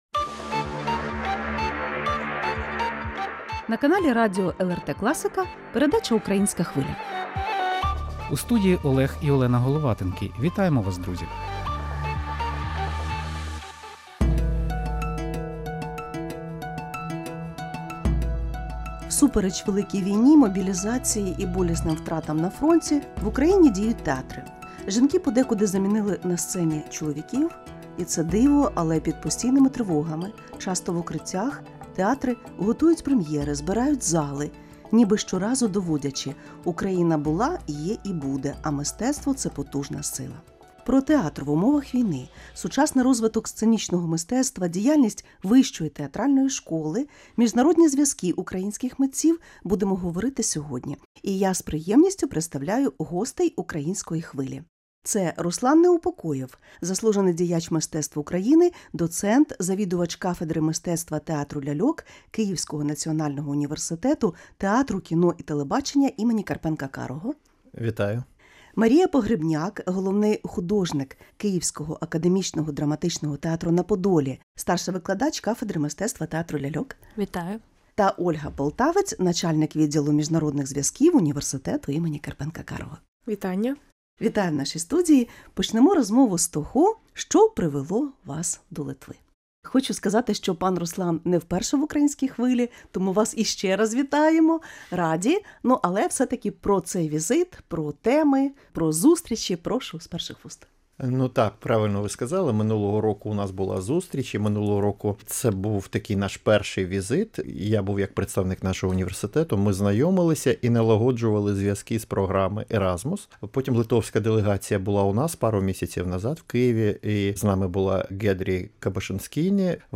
Про студентське навчання під час великої війни, розвиток українських театрів і прем’єри всупереч викликам сьогодення говоримо в передачі “Українська Хвиля” з гостями з України.